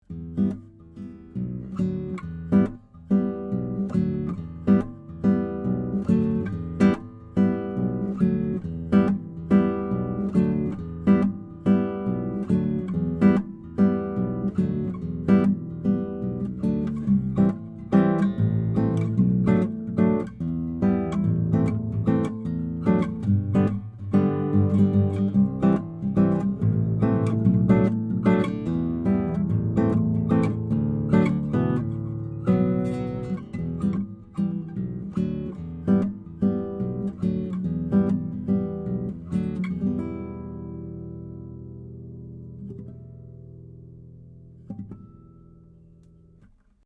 NEW 10-String Cathedral Guitar MODEL 40
Jazz Standard